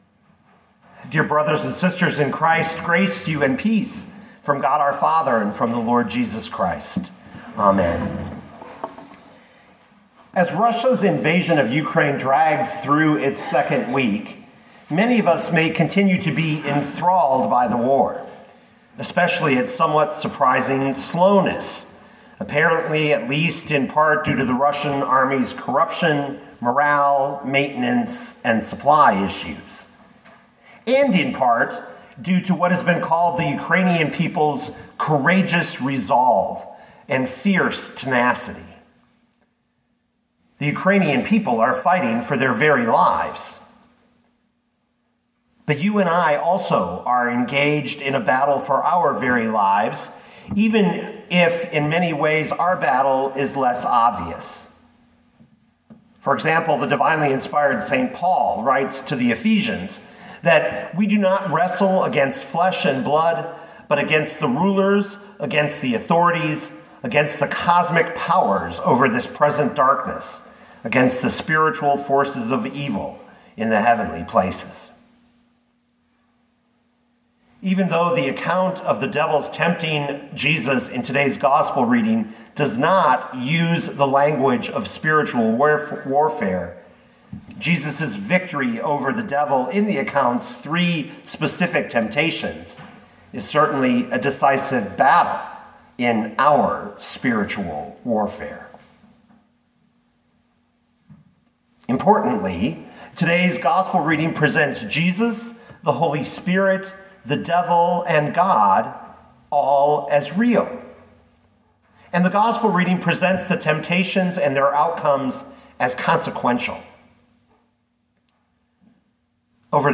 2022 Luke 4:1-13 Listen to the sermon with the player below, or, download the audio.